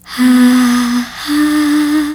Ha ha-B.wav